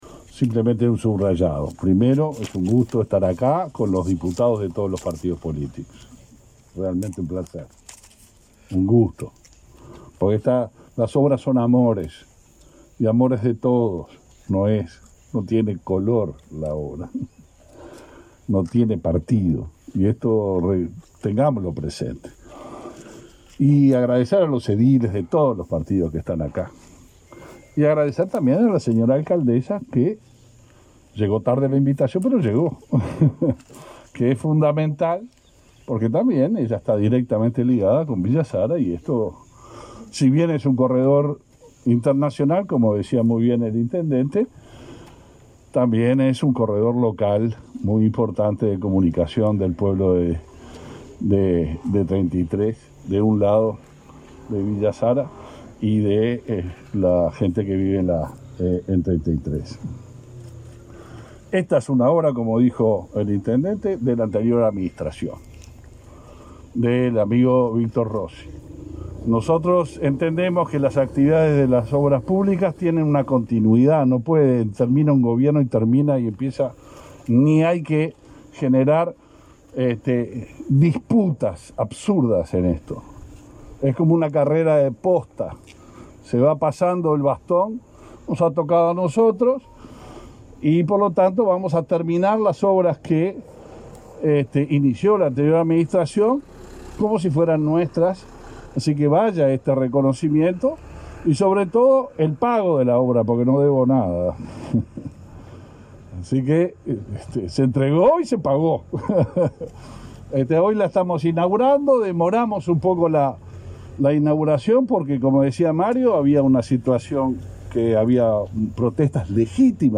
Declaraciones de Heber en la inauguración del puente Maestro Rubén Lena, en Treinta y Tres